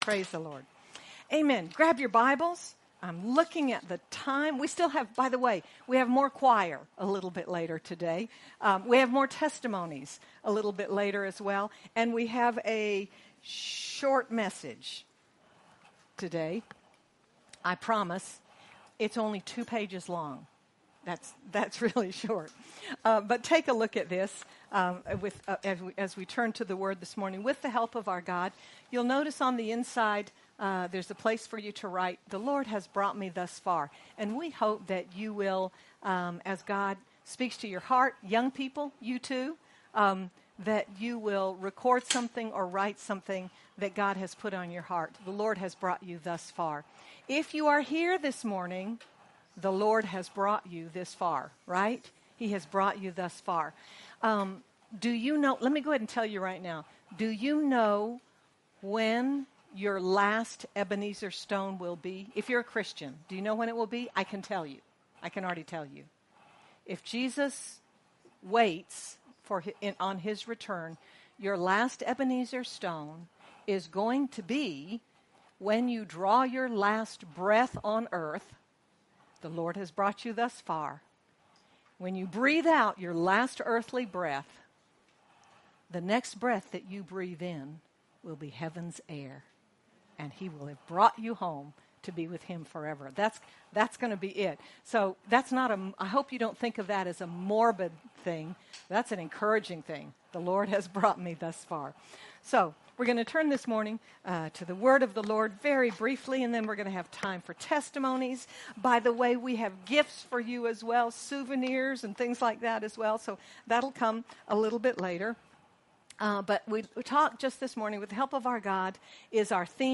Our help comes from the Lord, the maker of heaven and earth, and with His help, we will overcome and do mighty works! Sermon by